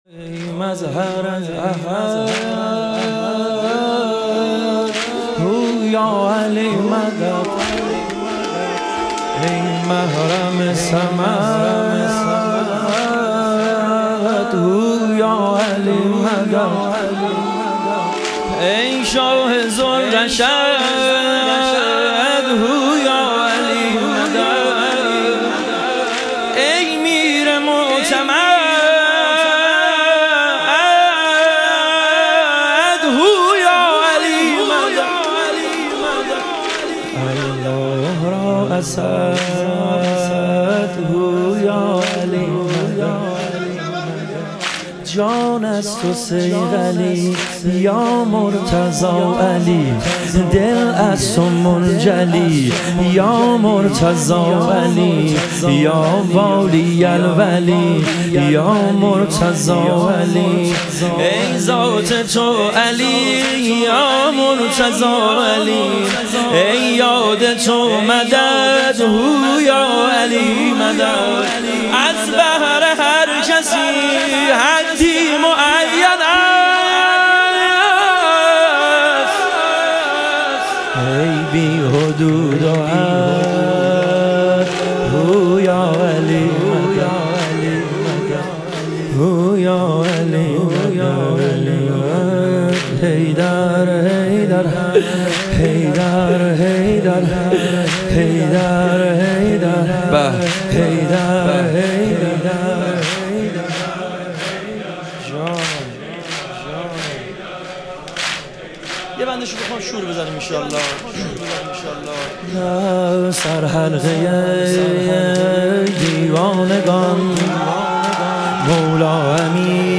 ایام فاطمیه اول - واحد